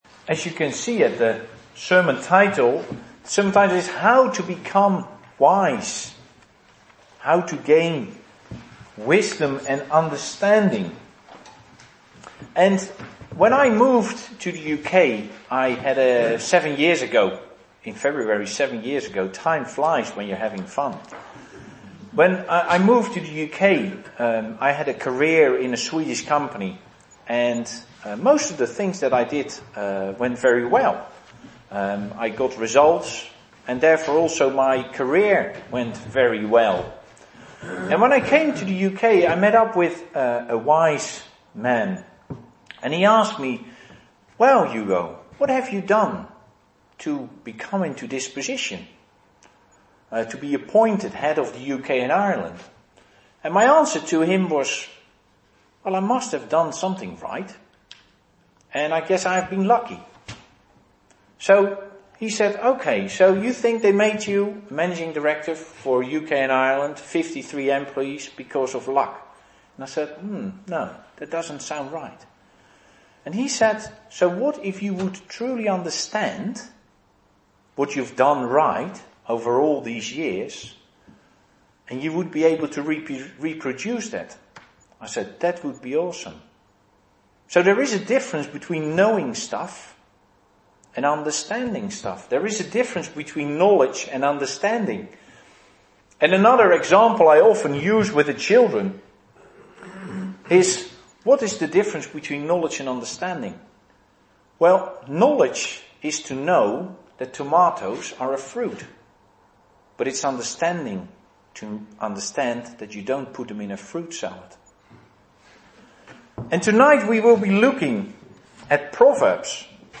2015 Service Type: Sunday Evening Speaker